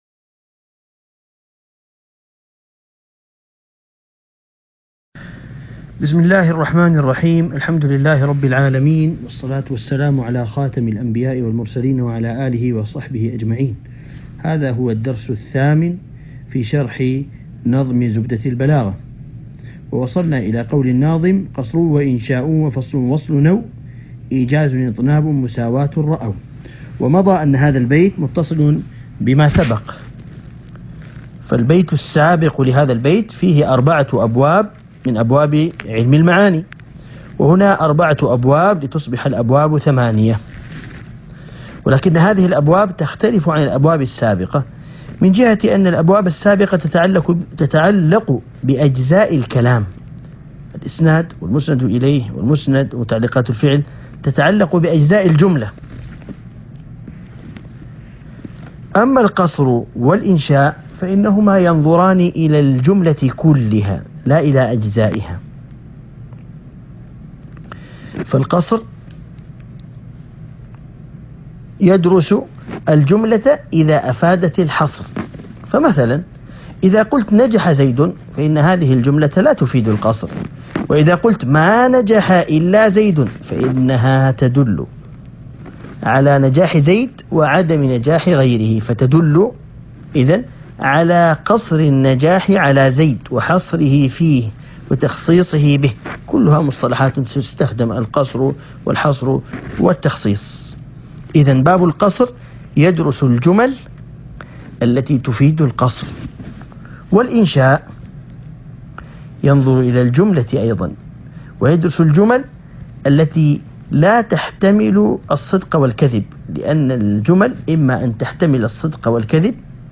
الدرس ( 8) شرح نظم زبدة البلاغة